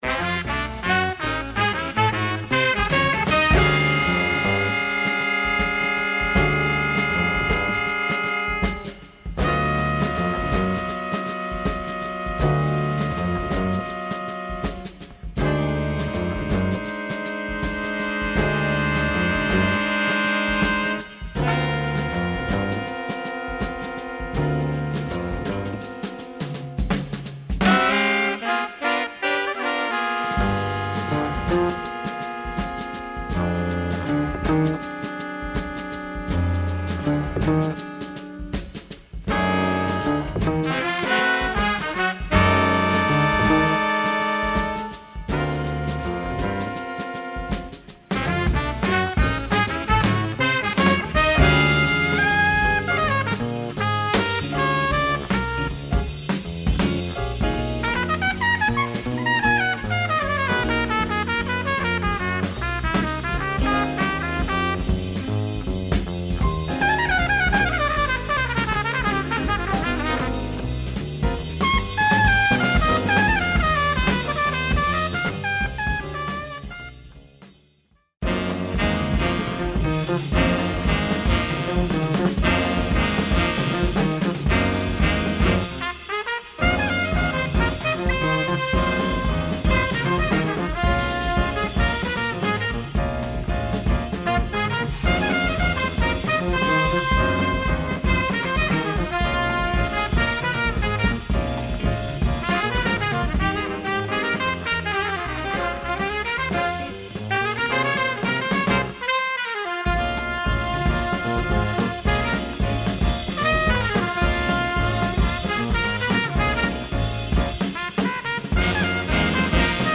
Spanish jazz, still under the radar.
Check the wooden bass !